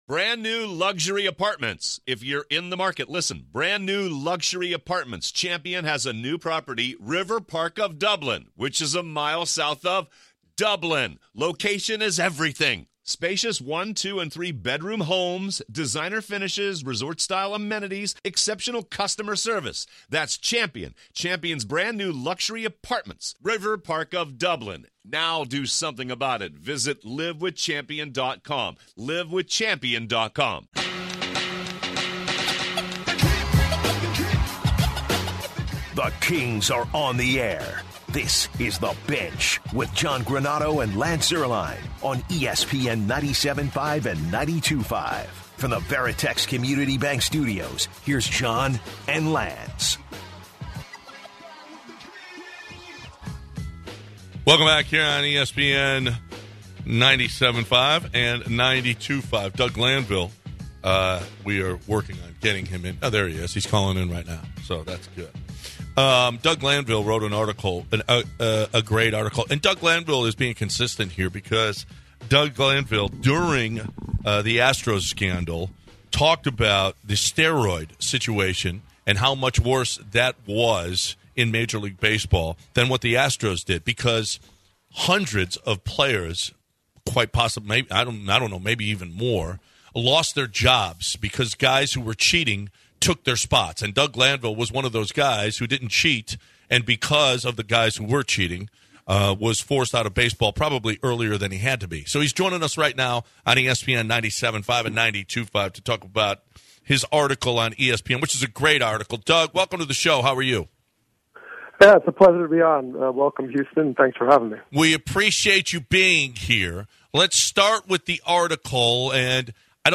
Doug Glanville called in to discuss the Hall of Fame steroid debate